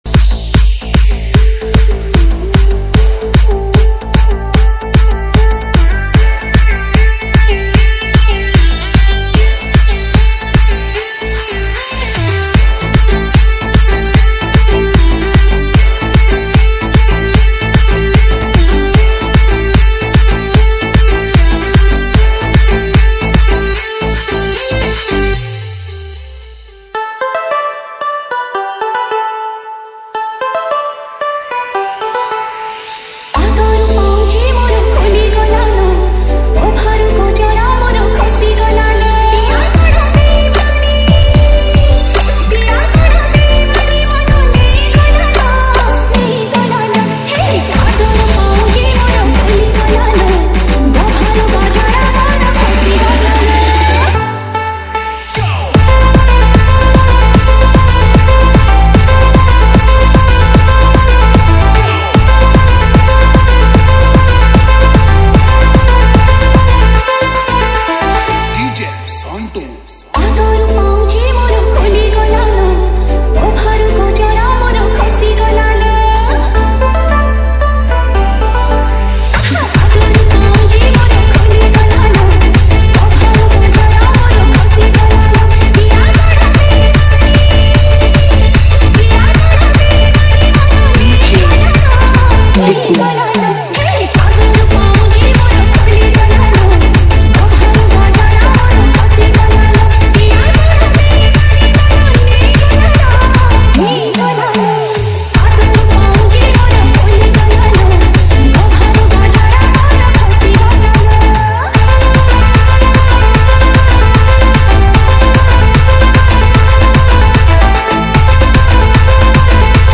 ODIA ROMANTIC DJ REMIX